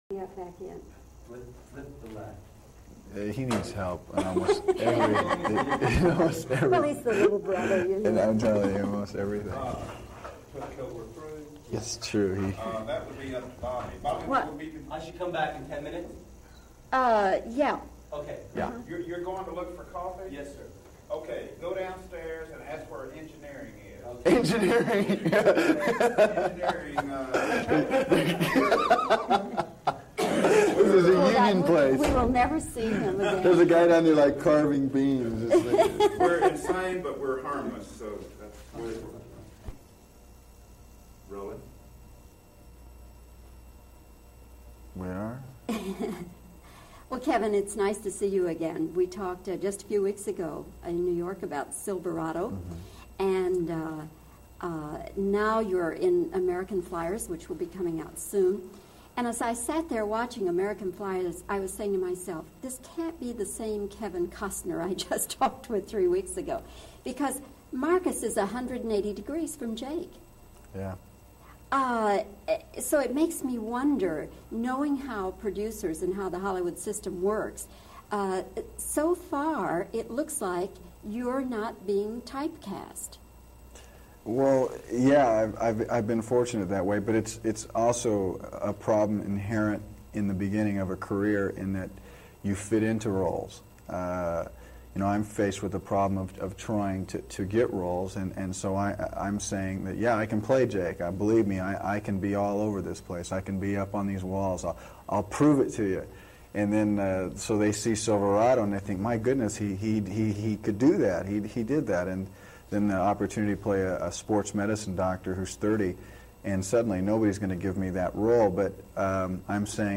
Here is an old interview he gave before the cycling movie, American Flyers came out which he starred in.